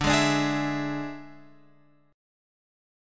Dsus2b5 chord {x 5 2 x 3 4} chord